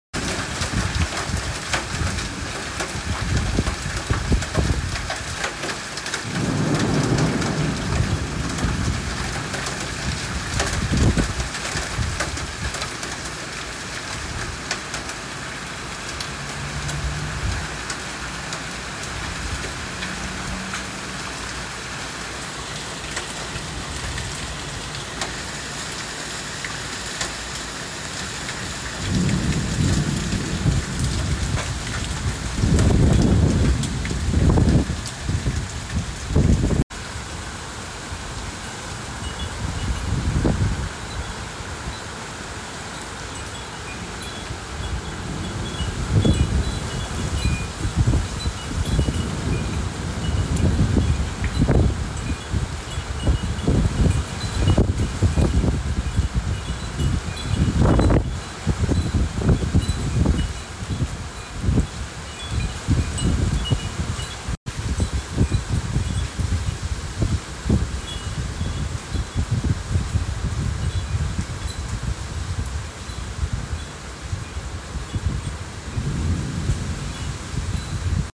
This Was A Scary Storm Sound Effects Free Download